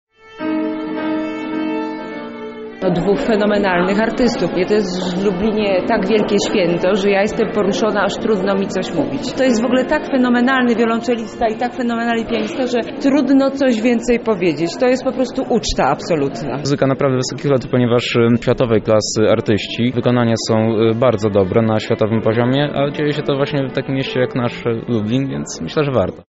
O swoich wrażeniach opowiadają słuchacze.
koncert-listopadowy.mp3